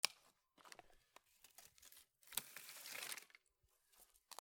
古びた木片 物音
/ M｜他分類 / L01 ｜小道具 /
『ミシ』